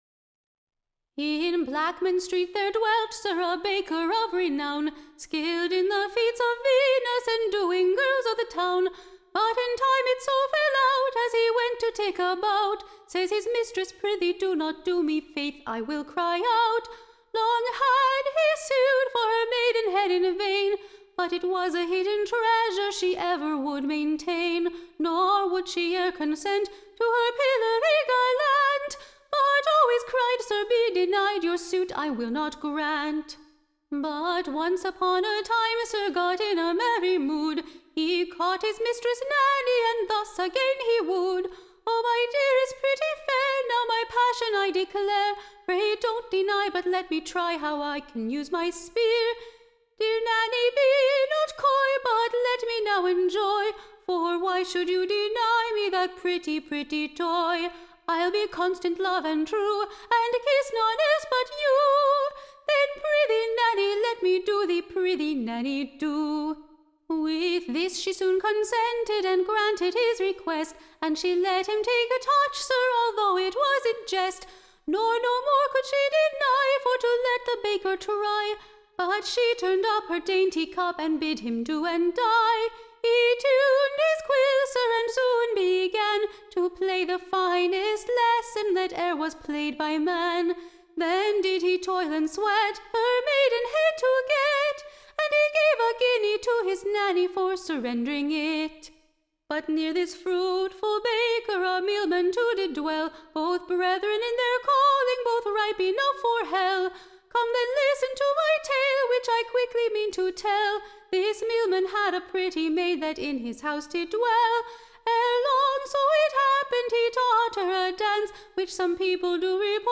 Recording Information Ballad Title A Copy of VERSES / OF A / Baker and a Mealman: / Giving an Account of their subtle Intreagues with their Mi- / stresses, and how the Mealman got his Maid with Child. Tune Imprint To the Tune of, The Scotch Hay-makers.